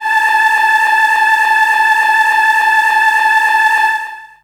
55be-syn18-a4.aif